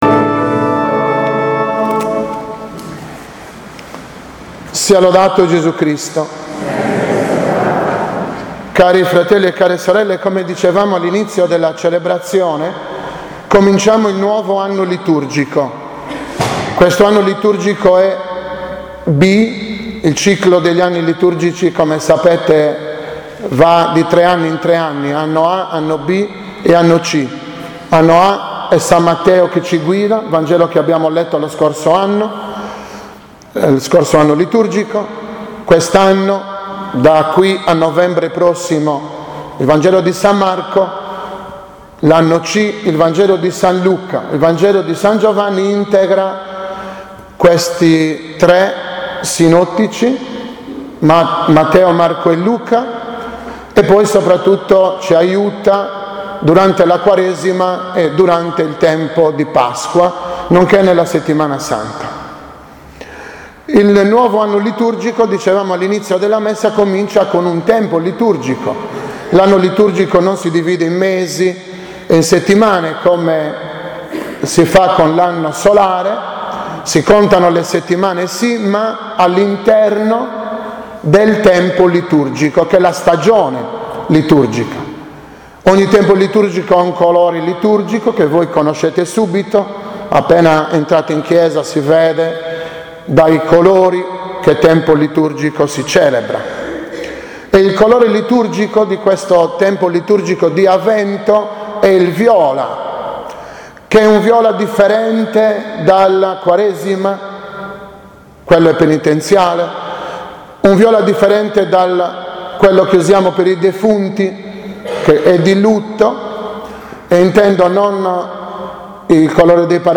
+ alla S. Messa dei giovani.